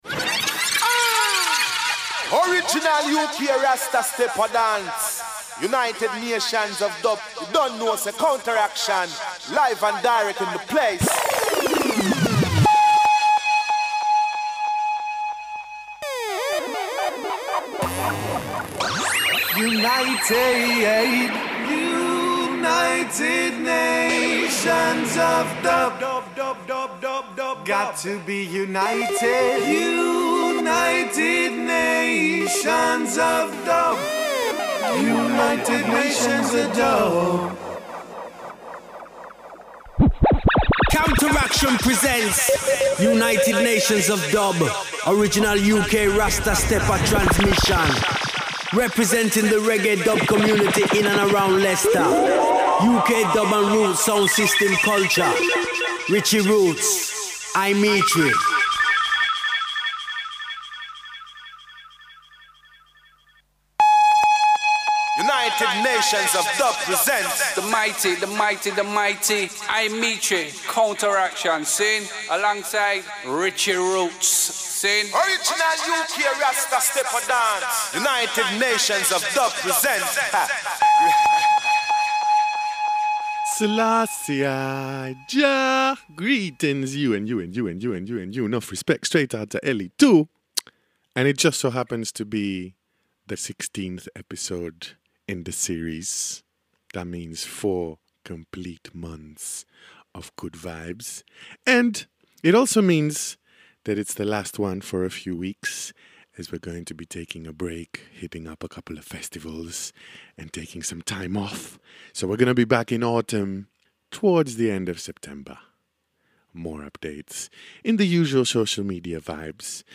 Reggae/Dub
Dub and Roots Sound System Culture